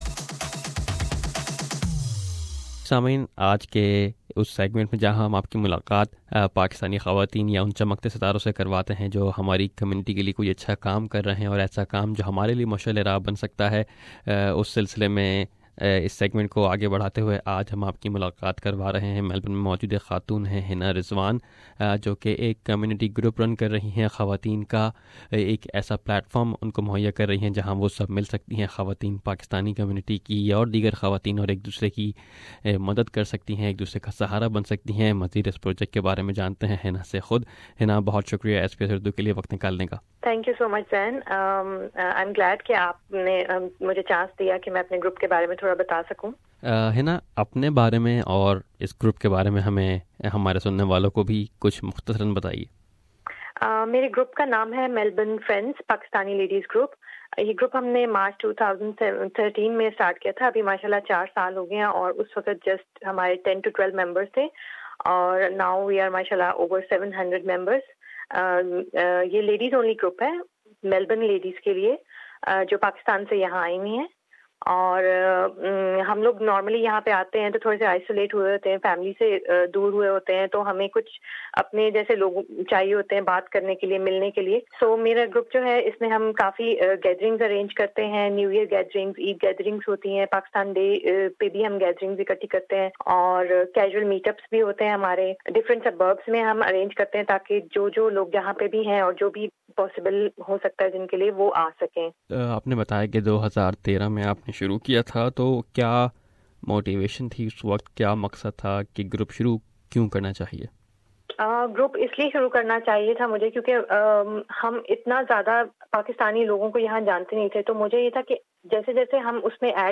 Listen to her interview for more information